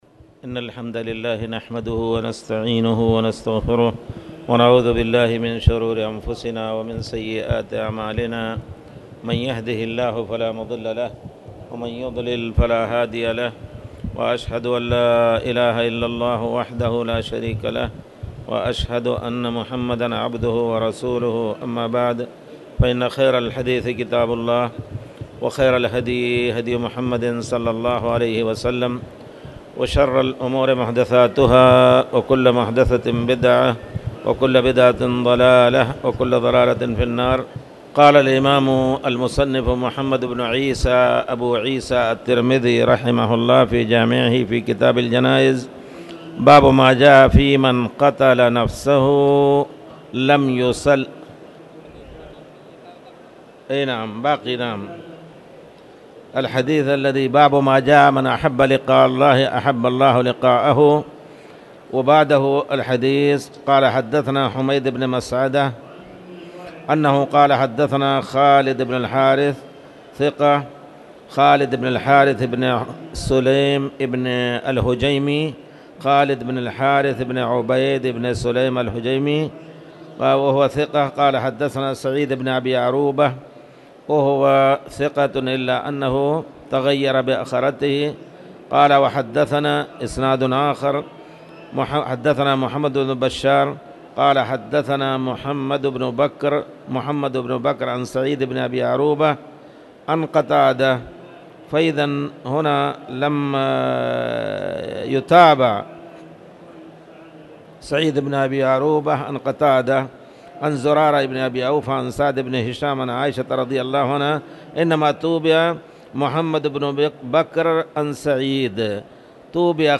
تاريخ النشر ١٦ شوال ١٤٣٧ هـ المكان: المسجد الحرام الشيخ